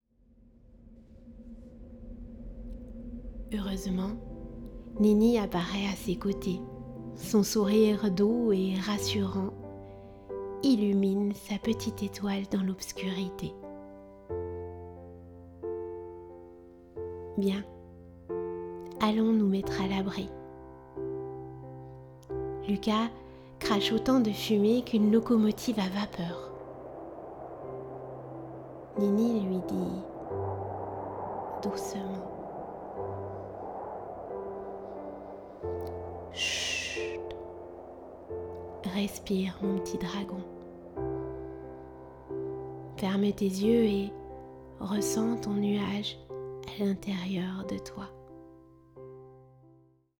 Un livre audio inclus